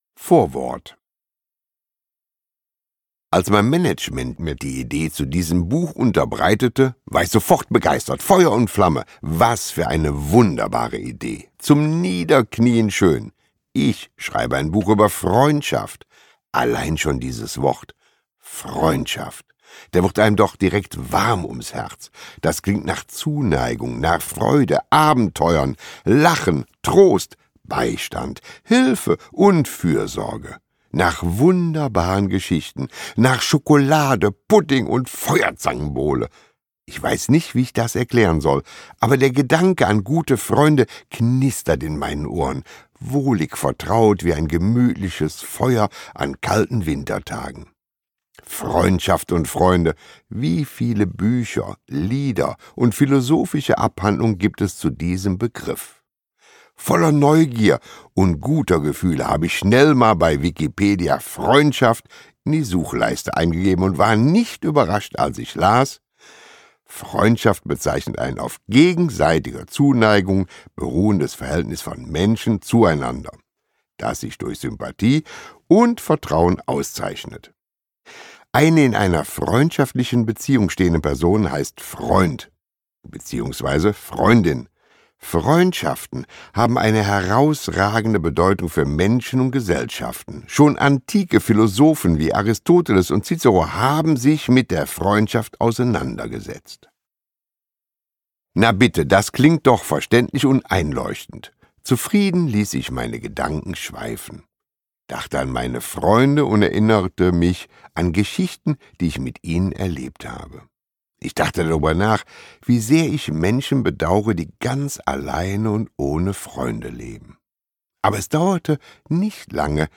Rezension: Horst Lichter spricht sein Buch – Zeit für Freundschaft ?!
Argon Hörbuch
Interpret: Horst Lichter